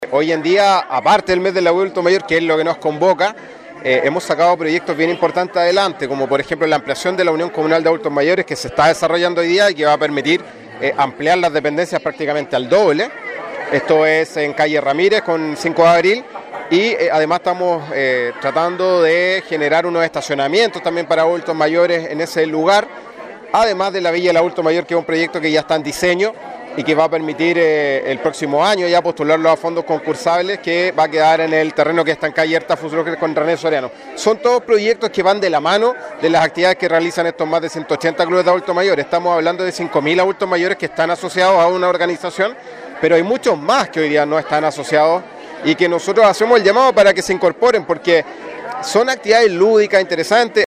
El concejal Miguel Arredondo, presidente de la comisión adulto mayor del concejo municipal, destacó el trabajo desarrollado por el Concejo, como  proyecto municipal “Villa del Adulto Mayor”, que considera la construcción de un Centro Diurno para Adultos Mayores y en el futuro casas tuteladas y un Establecimiento de Larga Estadía para Adultos Mayores.